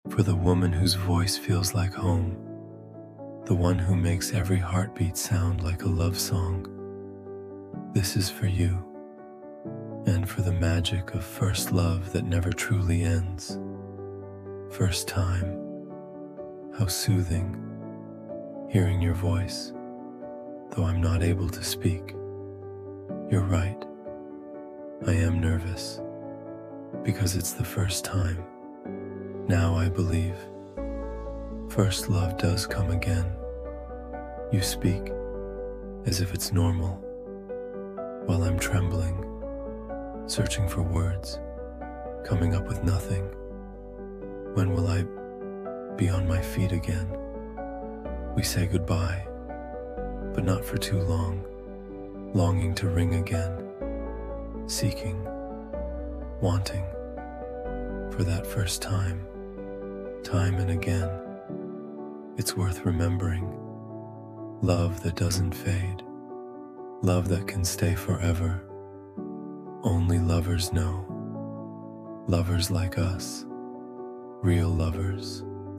First Time – Love Poem (Spoken Word)
First-Time-–-Love-Poem-Spoken-Wordmp3.mp3